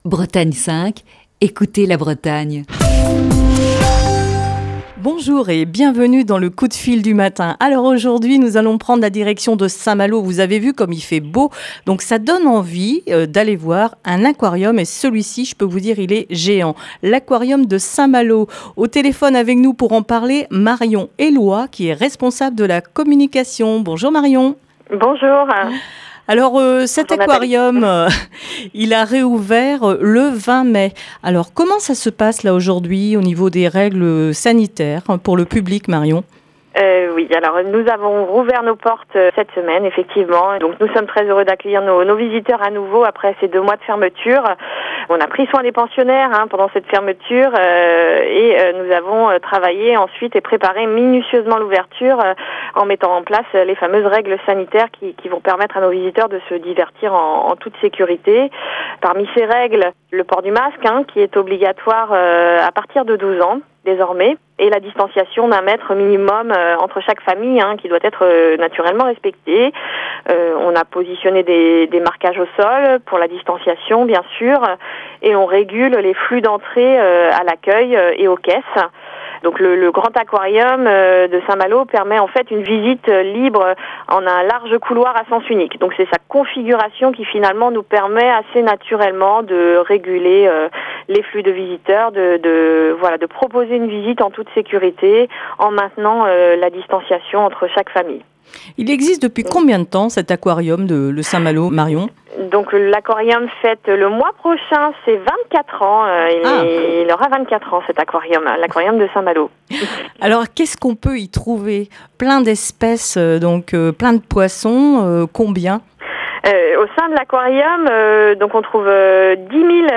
Aujourd'hui, dans le coup de fil du matin